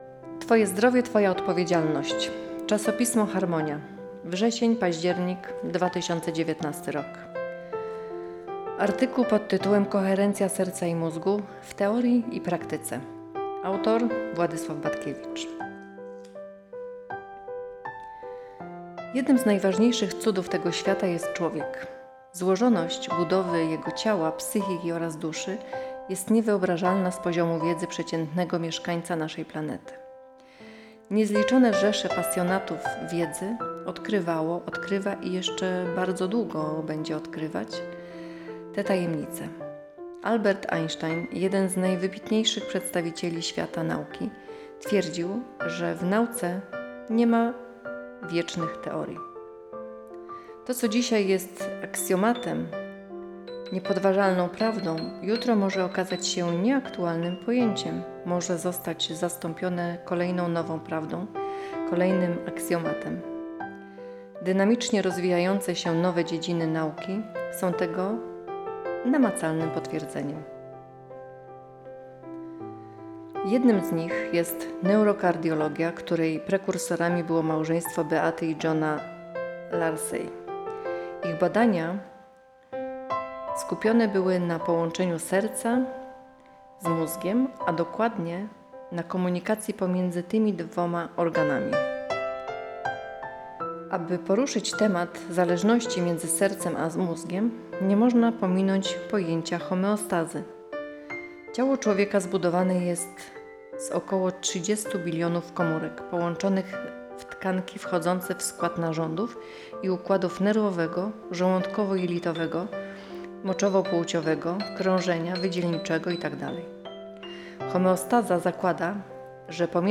Pliki czytane: artykuły o koherencji serca i mózgu z czasopisma Harmonia